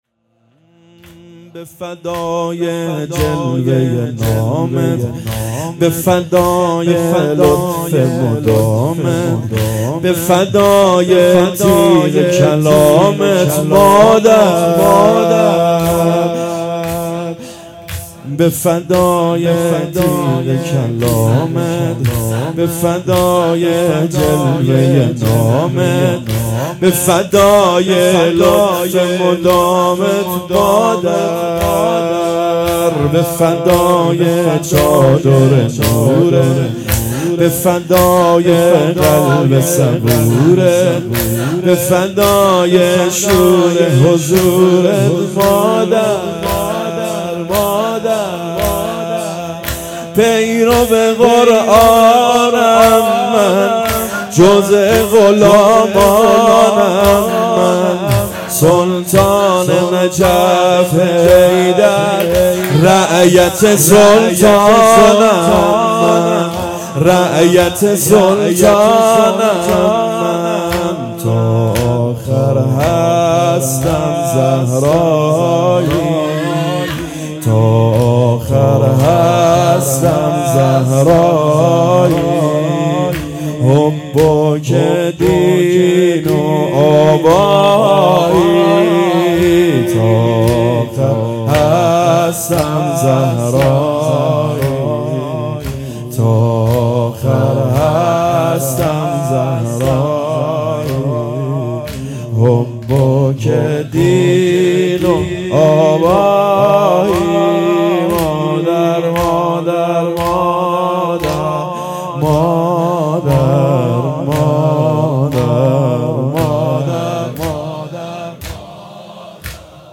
اقامه عزای شهادت حضرت زهرا سلام الله علیها _ دهه اول فاطمیه